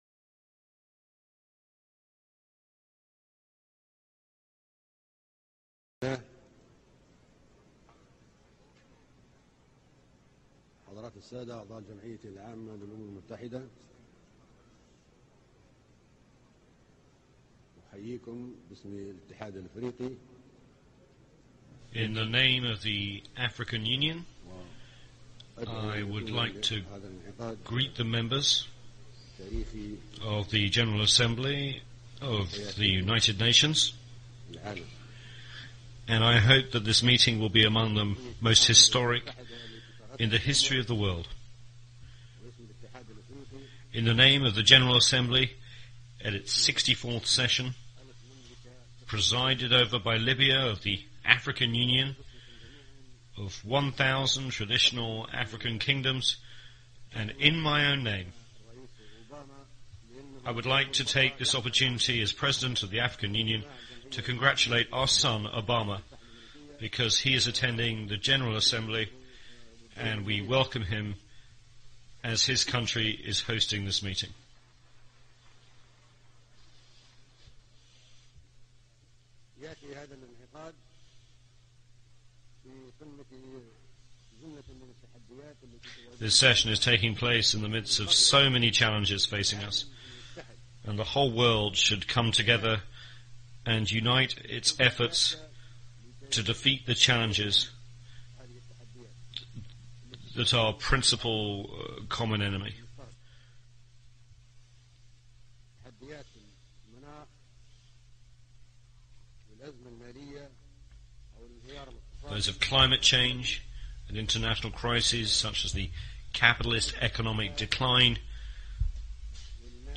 Mamour Gadaffi's Speech at the United Nations ( speech and translation starts at 20 seconds )